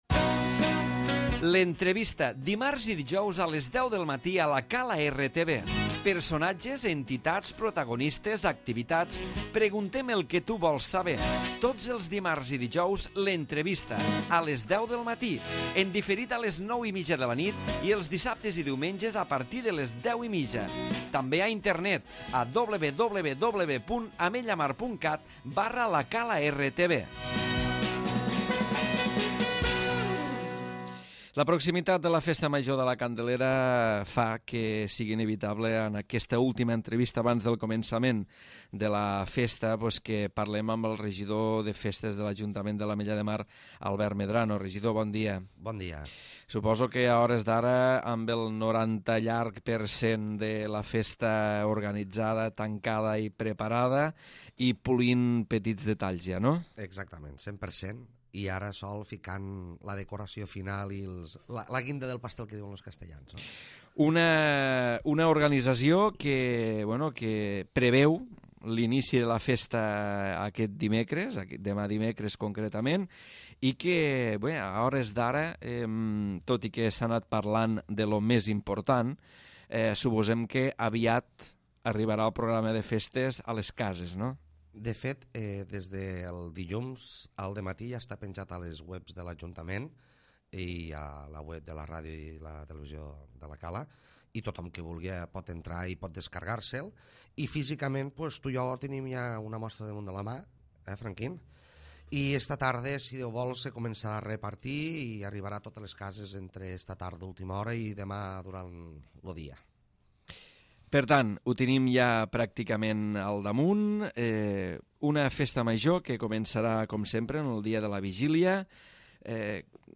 L'Entrevista
Albert Medrano, regidor de Festes de l'Ajuntament de l'Ametlla de Mar desgrana en aquesta entrevista el programa de la Festa Major de la Candelera 2012.